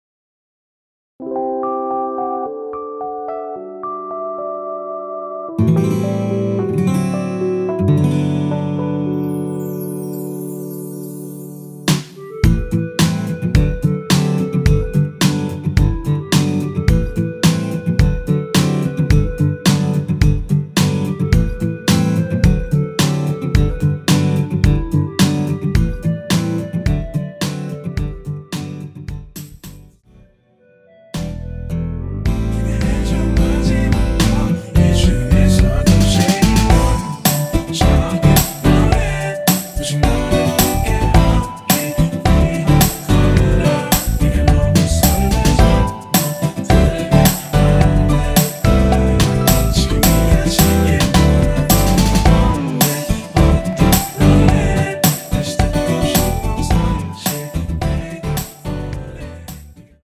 원키에서(-2)내린 멜로디와 코러스 포함된 MR입니다.(미리듣기 확인)
앞부분30초, 뒷부분30초씩 편집해서 올려 드리고 있습니다.
중간에 음이 끈어지고 다시 나오는 이유는